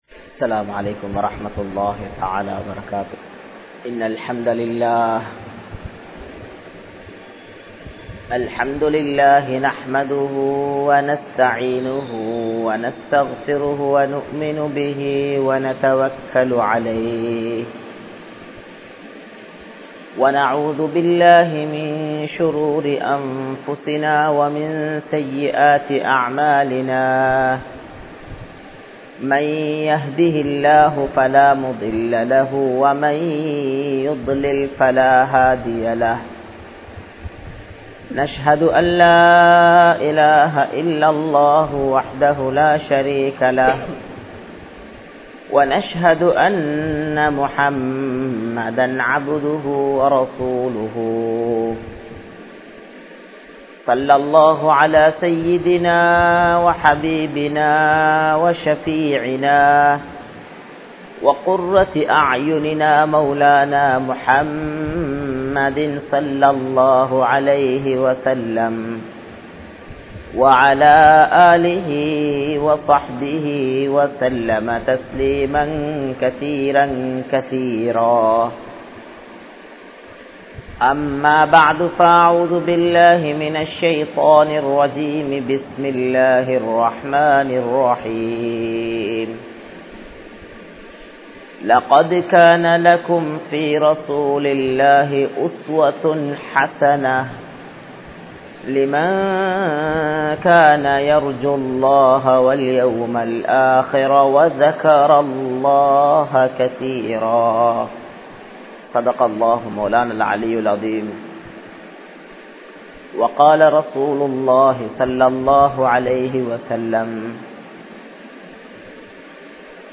Grand Jumua Masjith